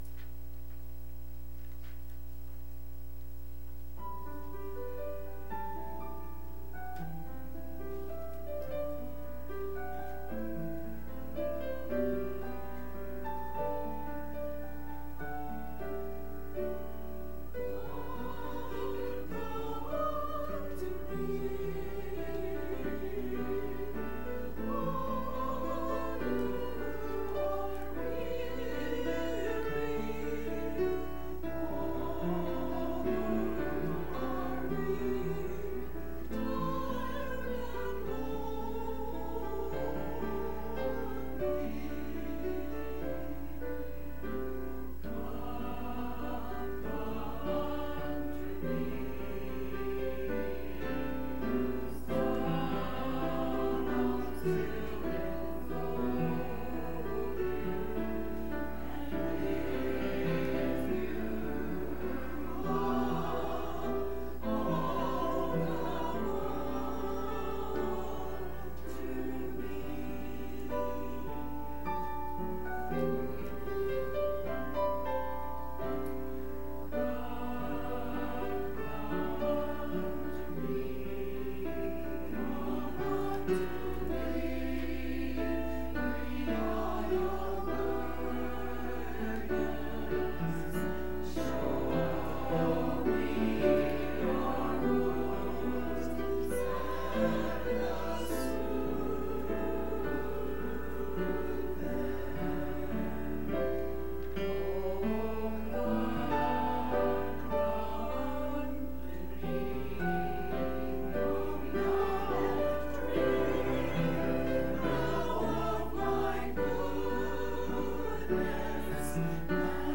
To hear the church choir praise God with music please click play below.